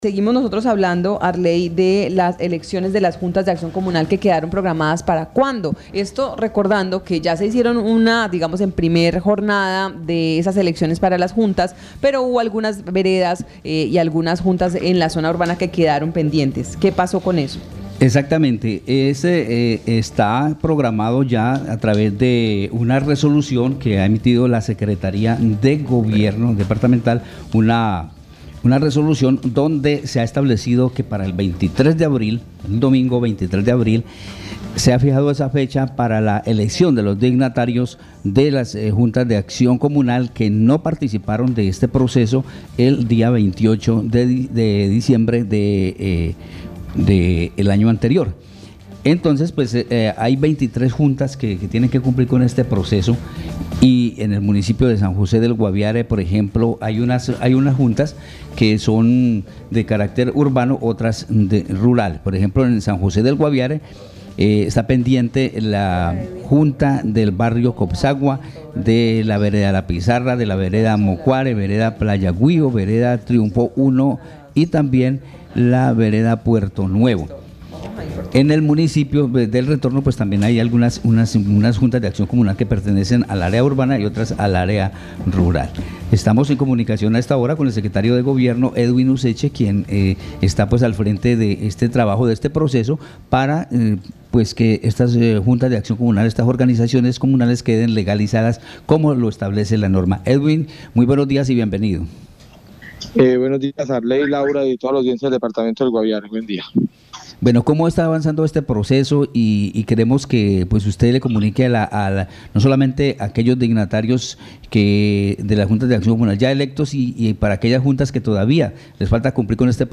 Escuche a Edwin Useche, secretario de Gobierno del Guaviare.